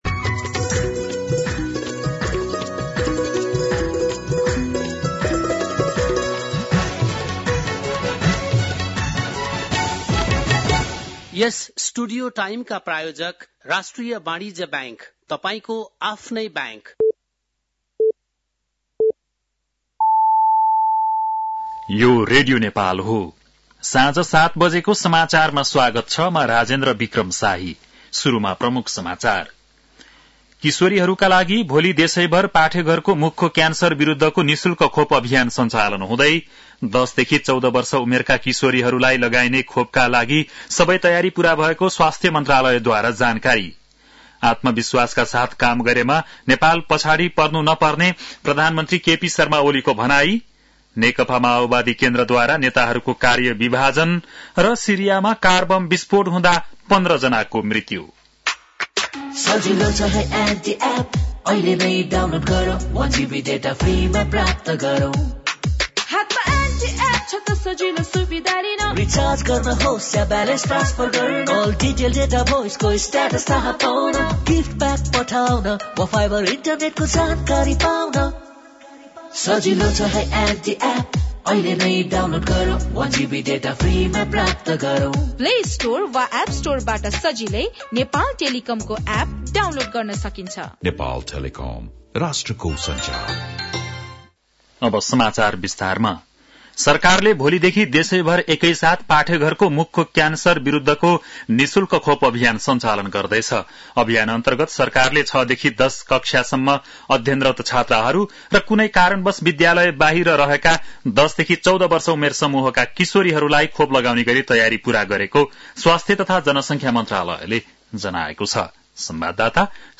बेलुकी ७ बजेको नेपाली समाचार : २२ माघ , २०८१
7-PM-Nepali-News-10-21.mp3